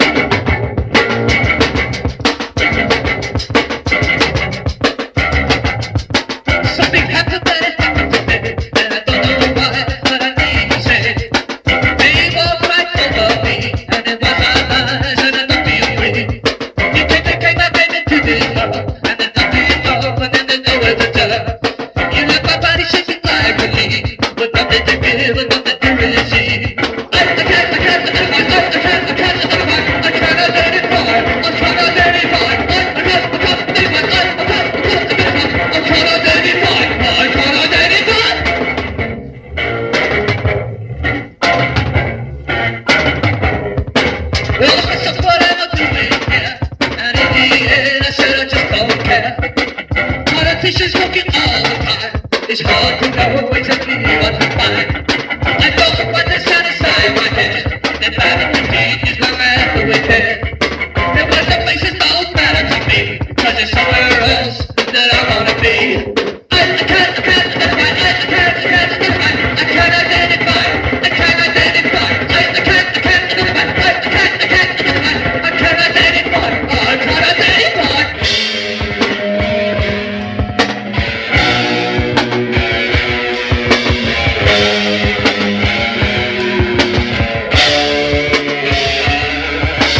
ROCK / 80'S/NEW WAVE. / NEW WAVE / AVANTGARD / EXPERIMENTAL
ポップになった2ND！